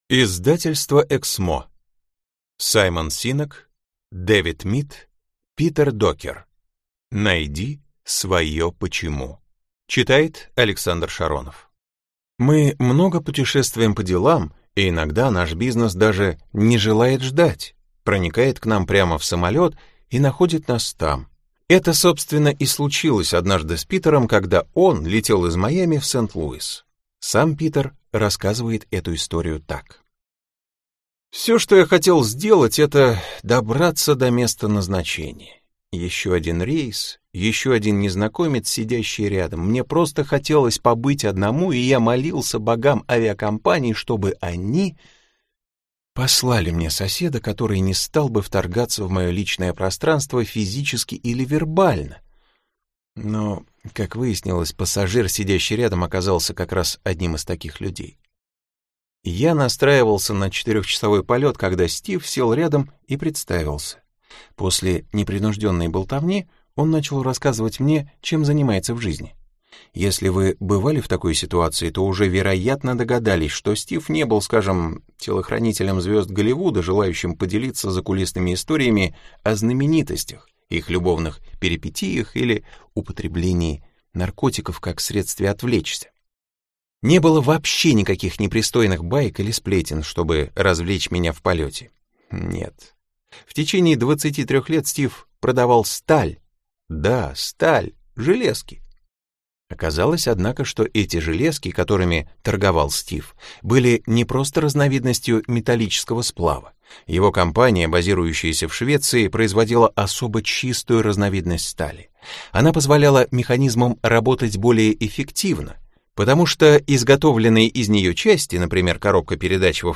Аудиокнига Найди свое «Почему?».